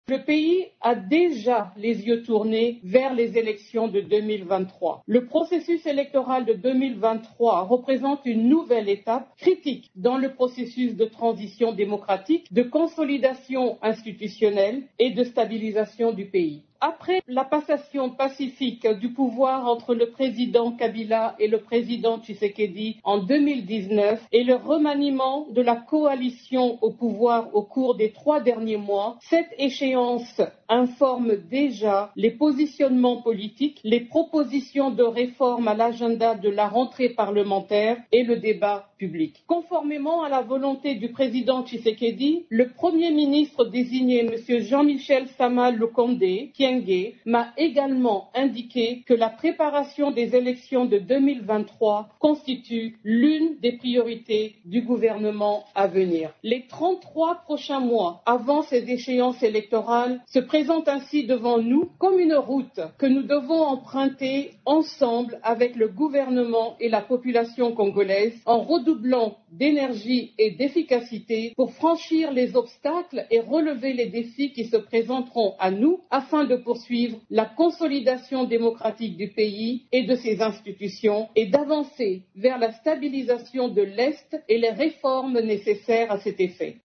La Représentante spéciale du Secrétaire général des Nations unies en RDC, Bintou Keita, l’a déclaré mardi 30 mars, lors de sa toute première intervention devant le Conseil de sécurité.